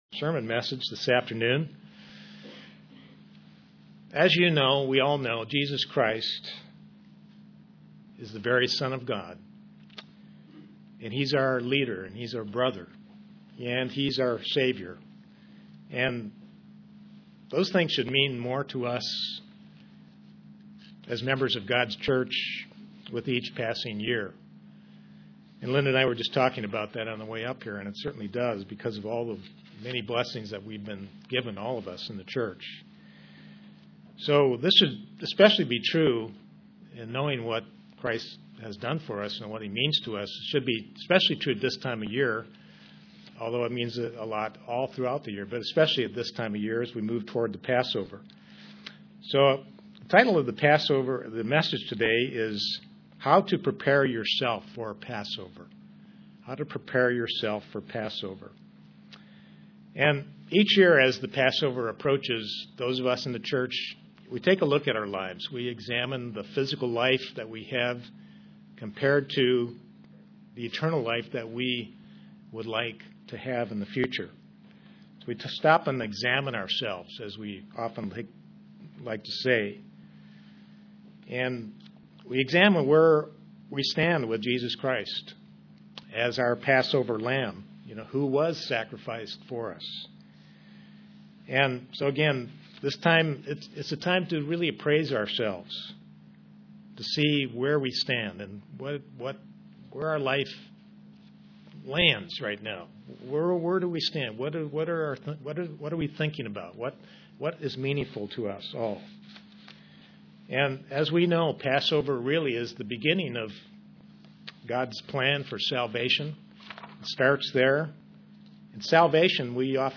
Given in Kingsport, TN
Print It is now time to consider and examine ourselves before the Passover UCG Sermon Studying the bible?